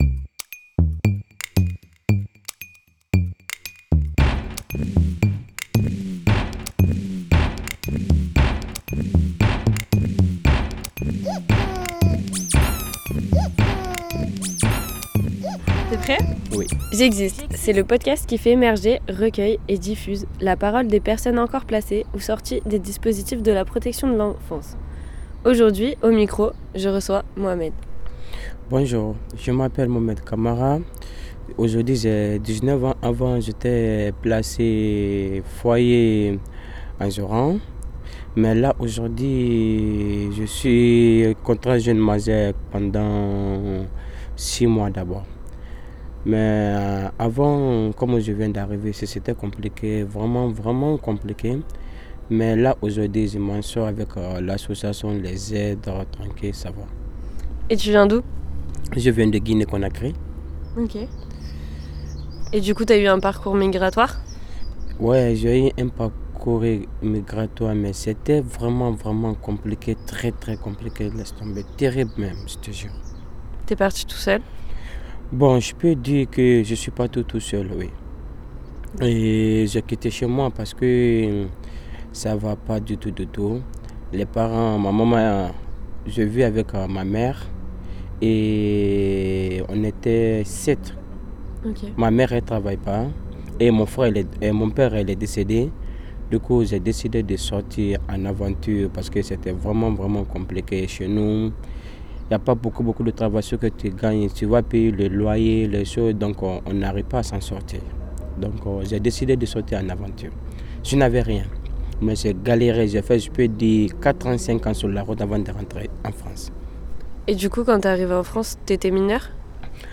Témoignages recueillis au micro